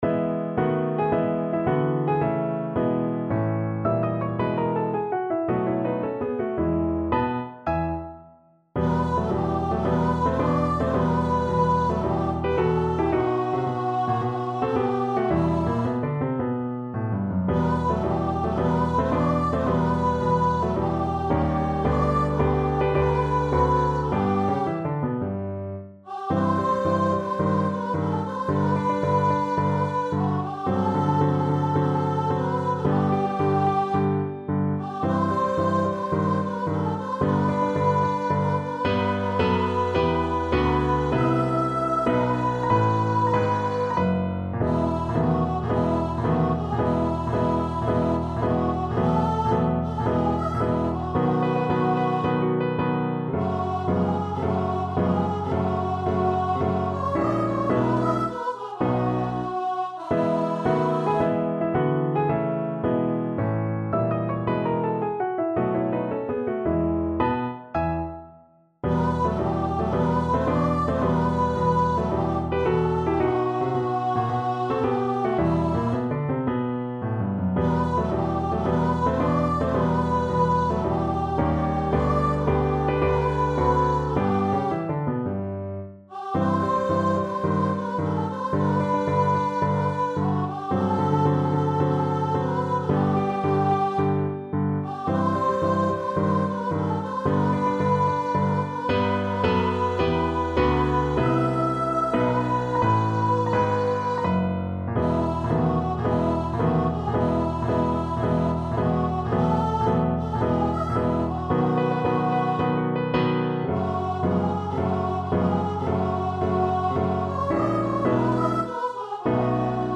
2/4 (View more 2/4 Music)
~ = 110 Marziale
D5-E6
Classical (View more Classical Voice Music)